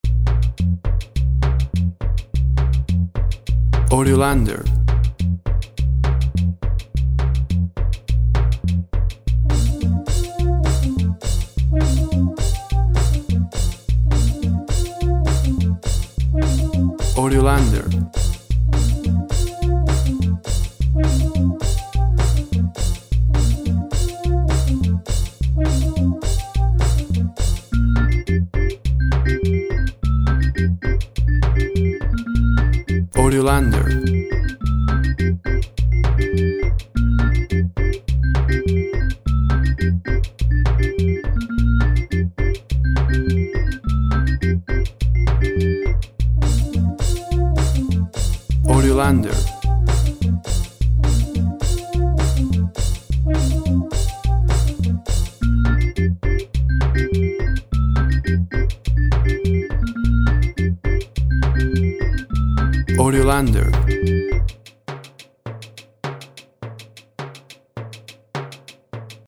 Fun music.
WAV Sample Rate 16-Bit Stereo, 44.1 kHz
Tempo (BPM) 104